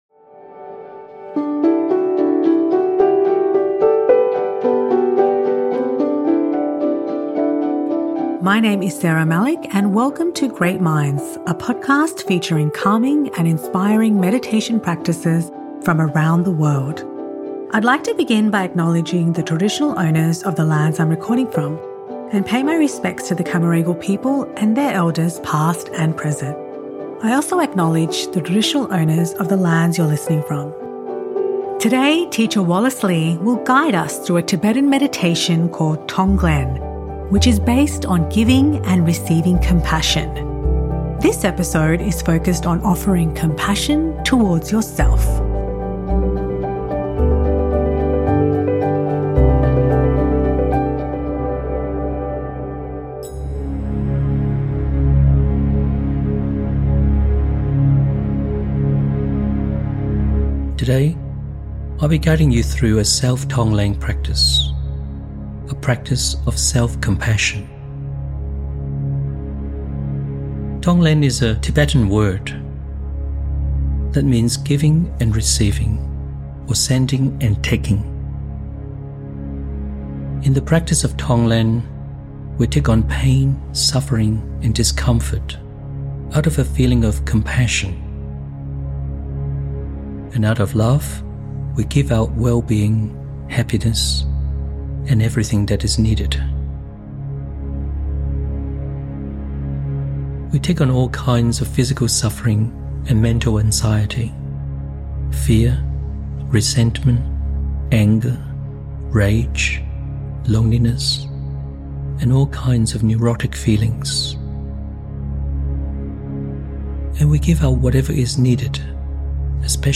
This episode is a guided practice of self-compassion.